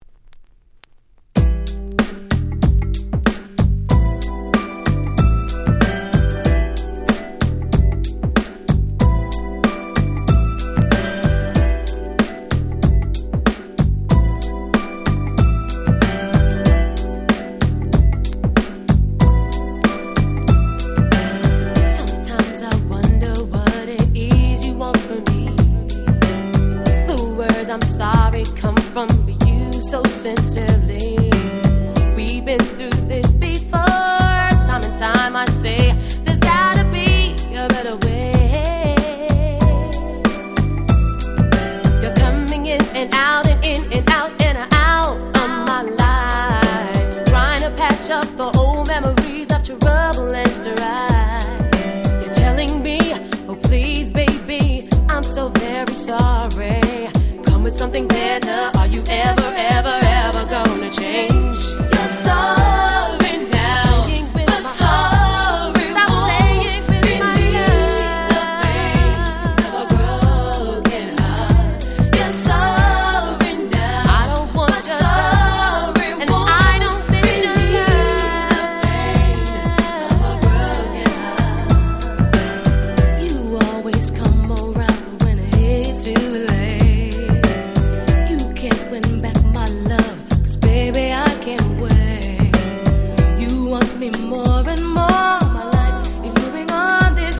94’ Very Nice R&B !!
アルバムの中では正直そこまで目立つ曲ではなかったですが、切ないメロディが堪らない最高のMid Dancerです！！